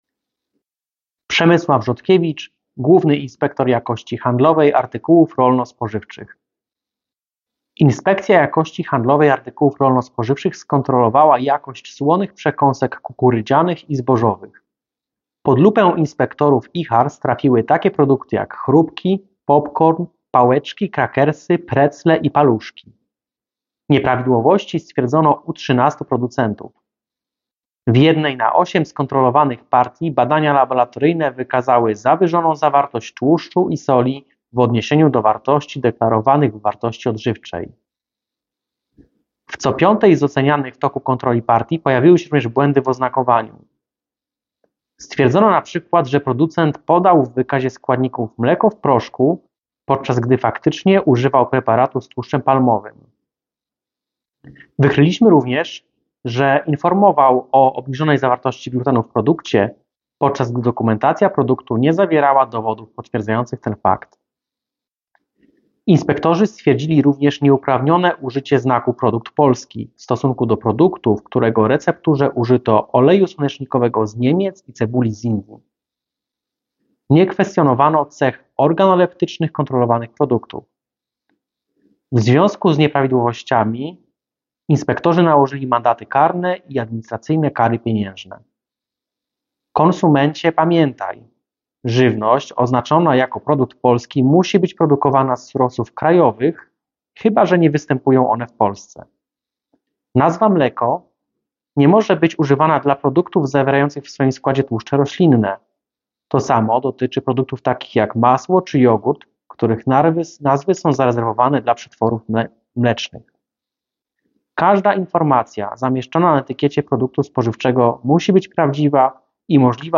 Wypowiedź Przemysława Rzodkiewicza GIJHARS dotycząca kontroli przekąsek słonych 2024-11-15